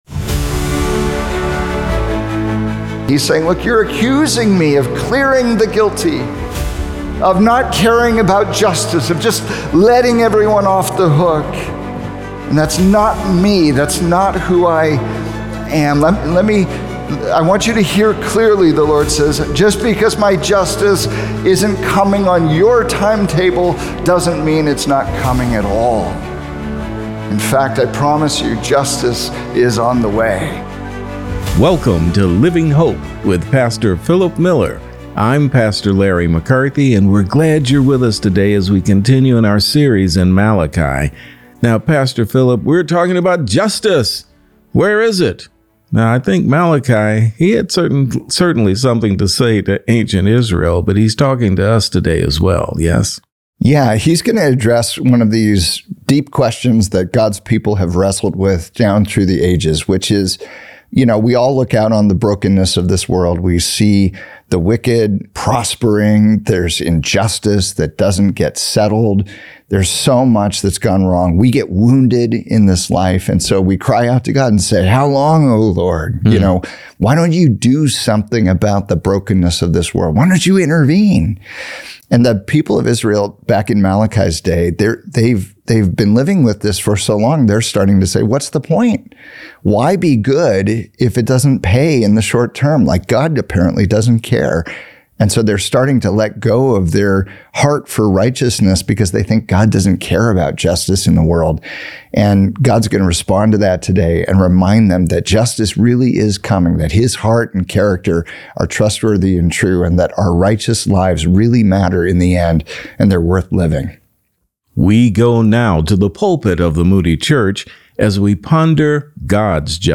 Why the Wicked Win—And Why You Should Not Give Up | Radio Programs | Living Hope | Moody Church Media